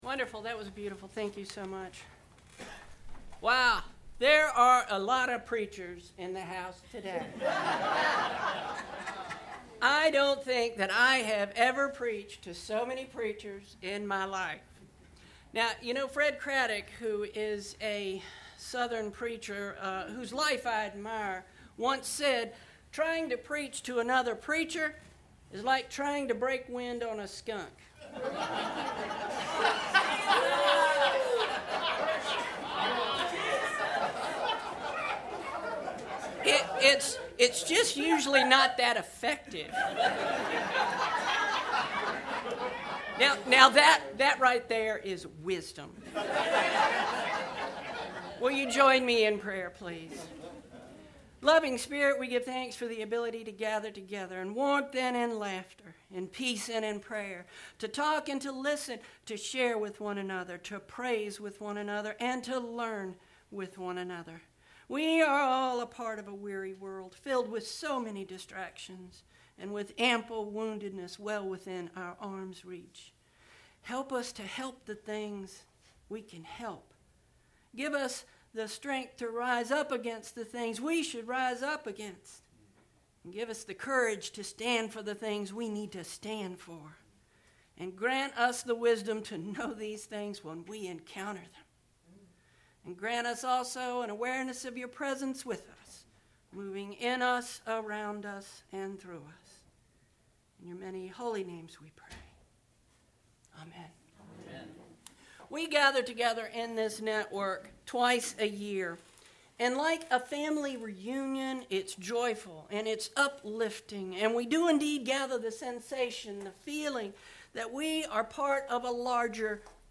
MCC Eastern Network Fall Gathering – Sat Oct 21 |
Worship Sermon “Gathering Wisdom” Preaching